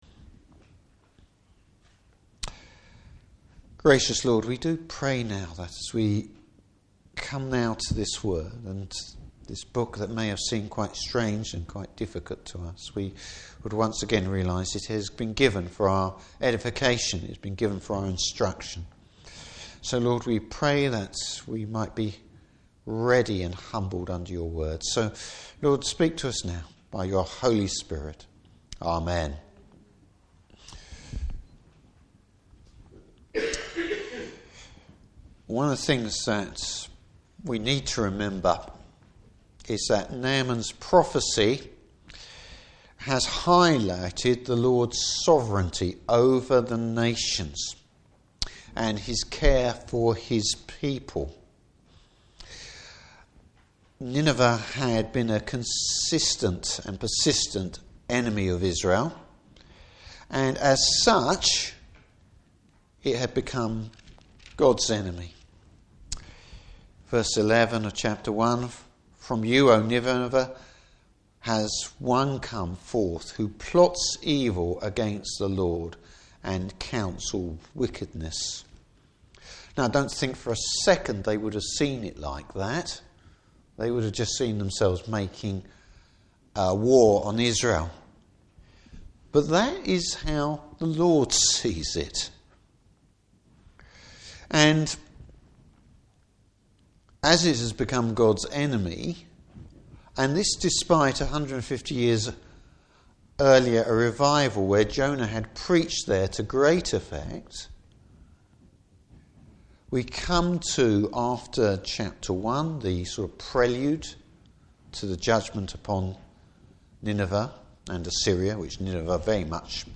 Passage: Nahum 3. Service Type: Evening Service Evil is punished, exposed and destroyed!